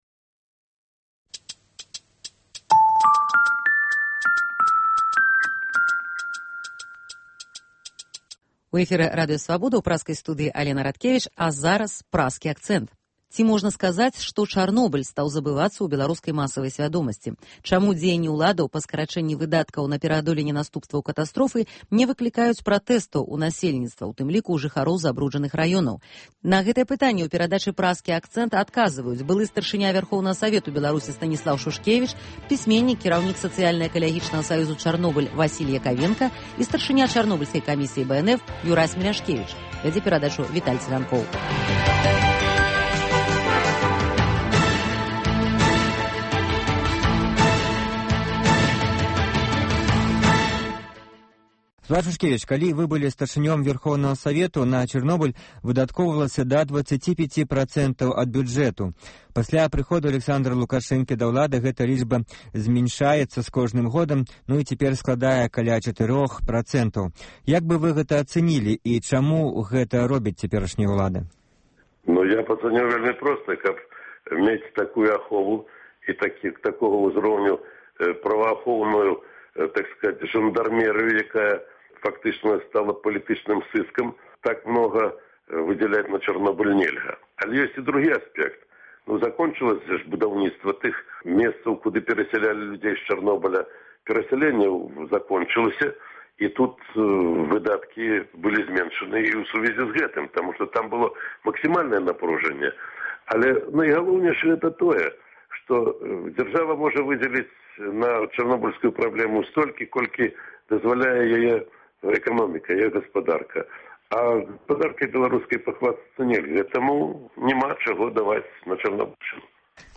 Круглы стол аналітыкаў, абмеркаваньне галоўных падзеяў тыдня